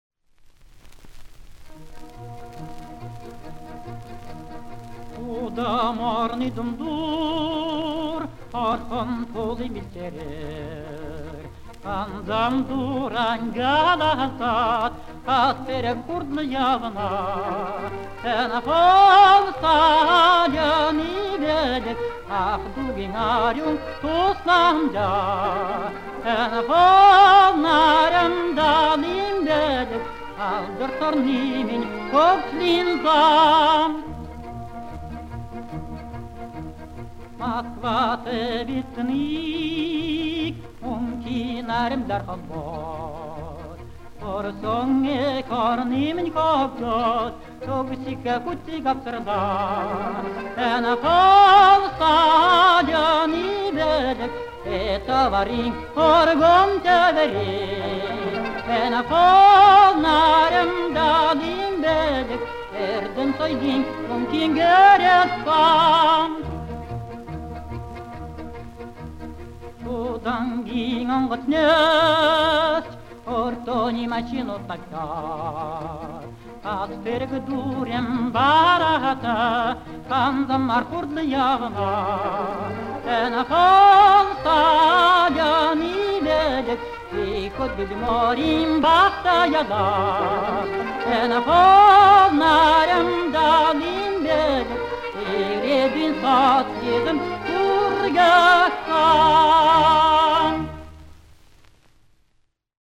Симфонический оркестр под упр.